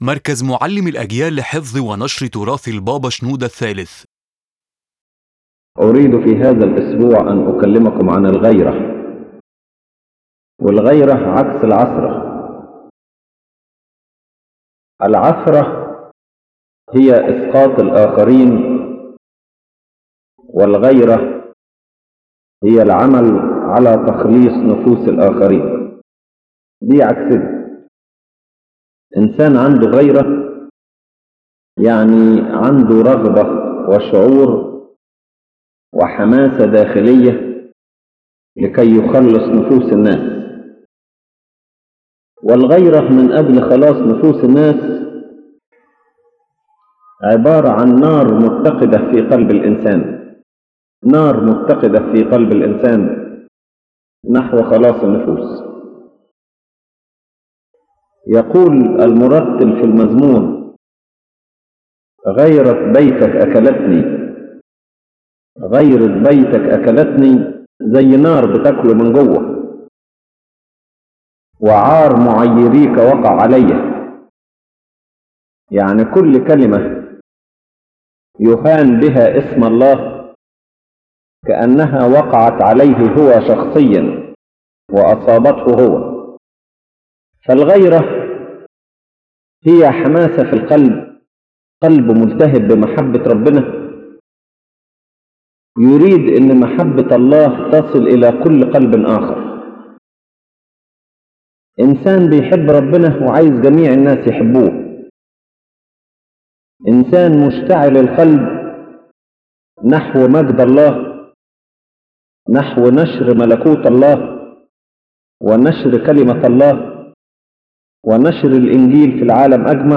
The lecture discusses the concept of holy zeal as a sacred spiritual fire in the heart of a person, driving him to love God and work for the salvation of others, in contrast to stumbling which causes others to fall.